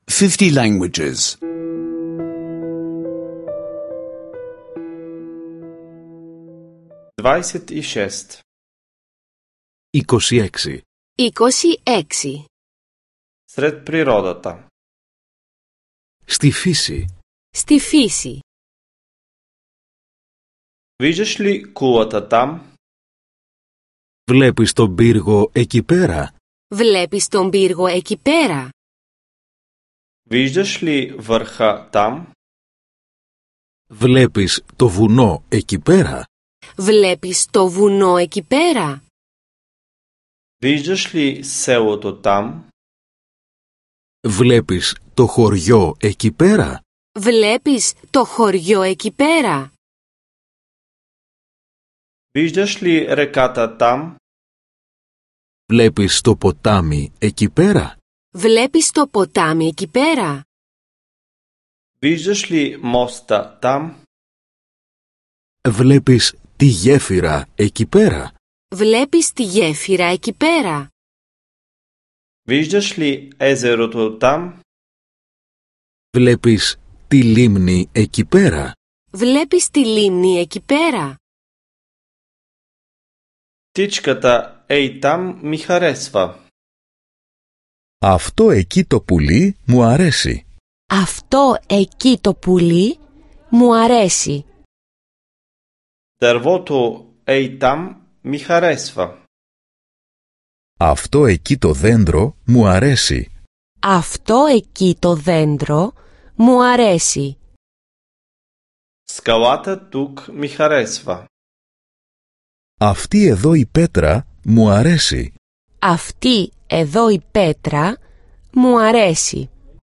Аудиокурс по гръцки език (за слушане онлайн)